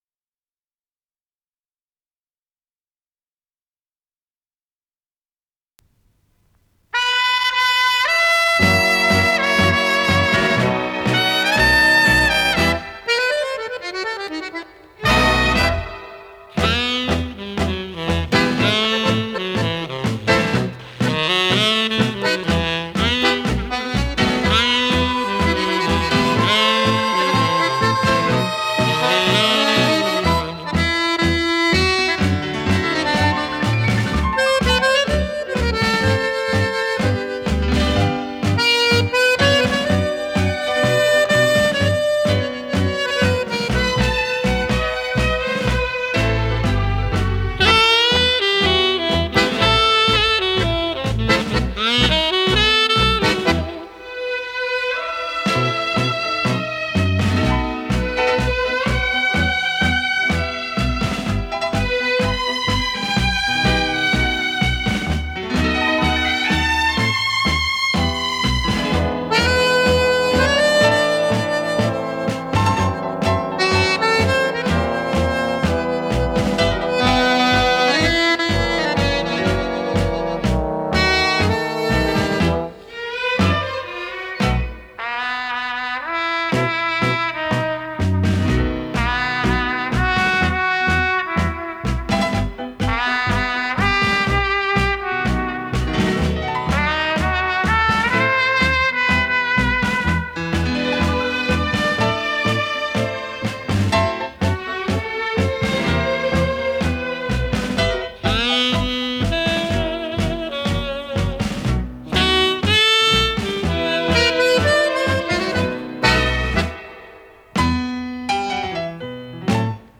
с профессиональной магнитной ленты
ПодзаголовокТанго
ВариантДубль моно